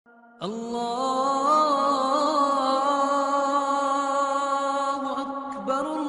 Tahajjud Allah se wafa hai Maulana Tariq Jameel bayan mp3 play online & download.